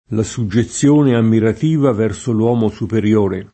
DOP: Dizionario di Ortografia e Pronunzia della lingua italiana
soggezione [SoJJeZZL1ne] s. f. — antiq. o pop. suggezione [SuJJeZZL1ne], nel sign. ora di «attenzione preoccupata», ora di «timore reverenziale» (non in quello di «esser soggetto, esser subordinato»): doveva costeggiare il confine, per tenere in suggezione i lanzichenecchi [dov%va koSteJJ#re il konf&ne, per ten%re in SuJJeZZL1ne i lanZiken%kki] (Manzoni); la suggezione ammirativa verso l’uomo superiore [